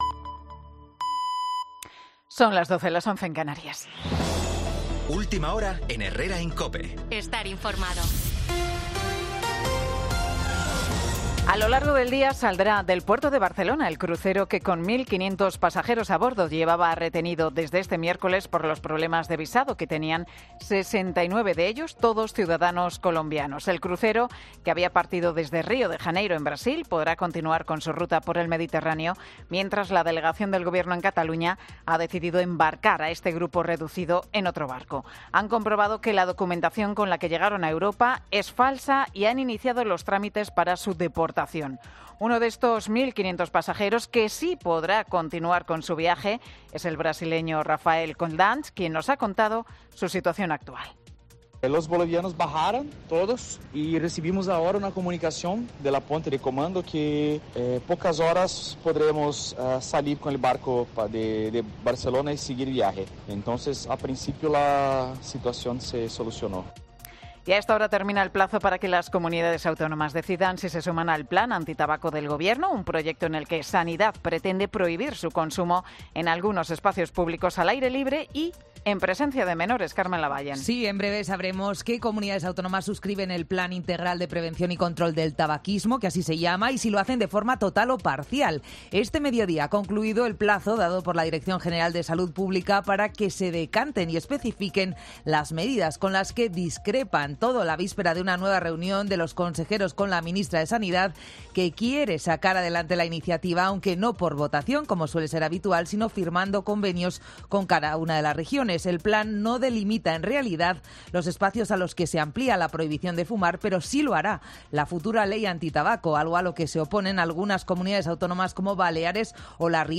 Boletín 12.00 horas del 4 de abril de 2024